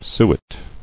[su·et]